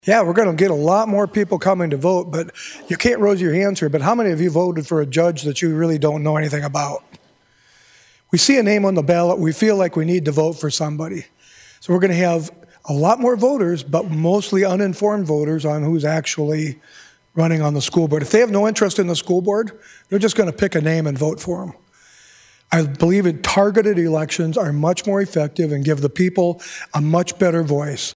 Senator Kevin Jensen says the bill could create more uninformed voters…